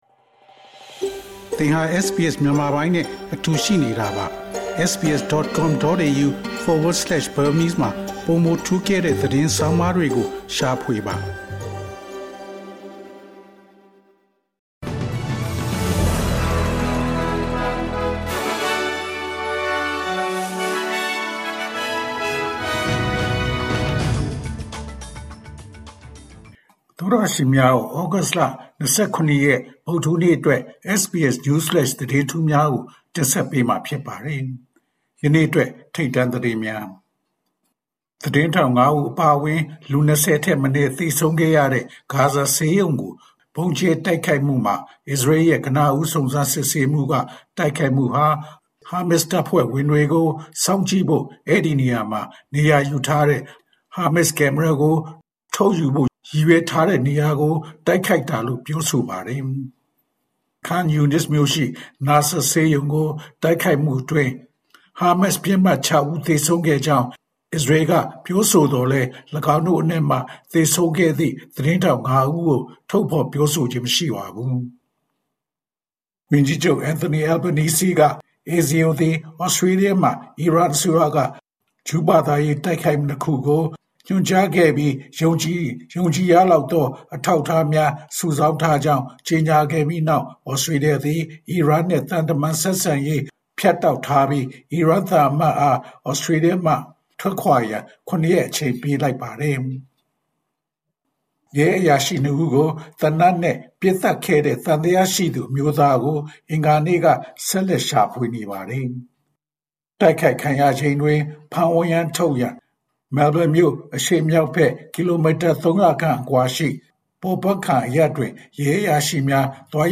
SBS မြန်မာ ၂၀၂၅ ခုနှစ် ဩဂုတ်လ ၂၇ ရက် နေ့အတွက် News Flash သတင်းများ။